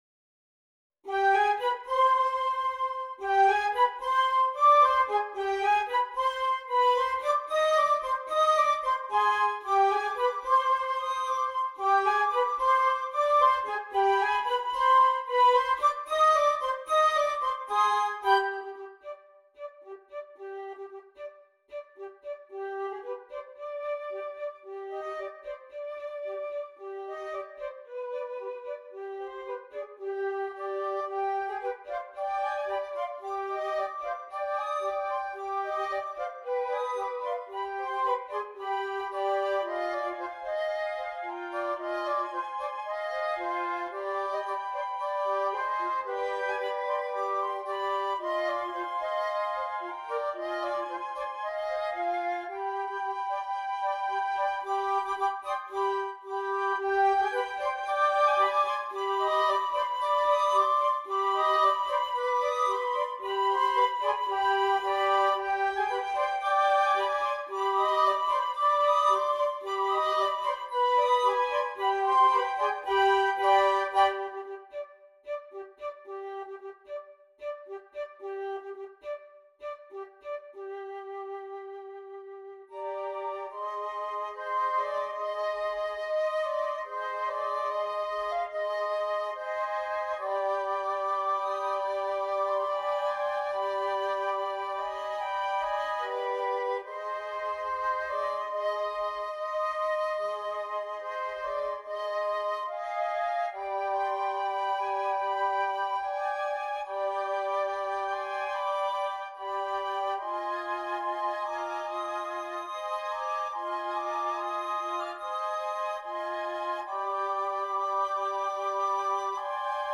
5 Flutes